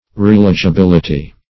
-- Re*el`i*gi*bil"i*ty (r[=e]*[e^]l`[i^]*j[i^]*b[i^]l"[i^]*t[y^]), n. [1913 Webster]